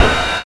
TRA04RIDE.wav